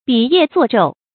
俾夜作晝 注音： ㄅㄧˇ ㄧㄜˋ ㄗㄨㄛˋ ㄓㄡˋ 讀音讀法： 意思解釋： 把夜晚當作白晝一般利用，夜以繼日。